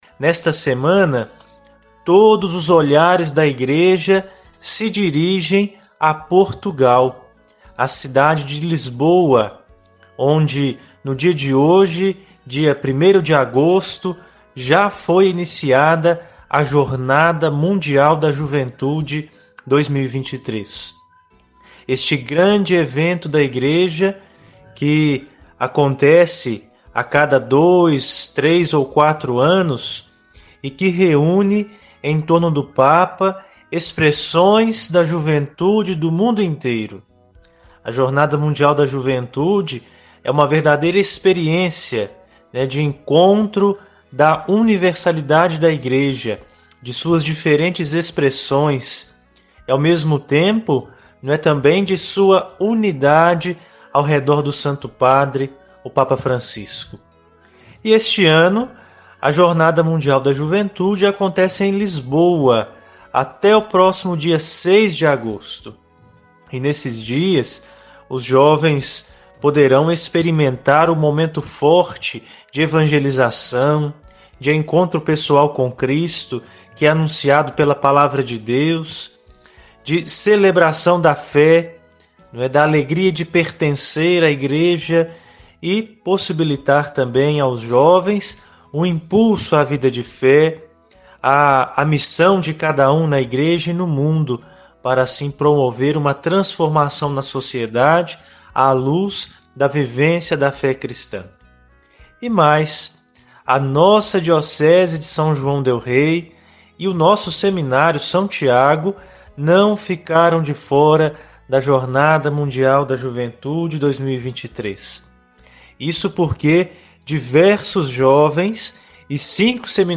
Outros seminaristas também gravaram seus testemunhos e relataram a experiência em Lisboa.
Seminarista-fala-sobre-a-importancia-da-JMJ-e-interage-com-jovens-em-Liboa2-online-audio-converter.com_.mp3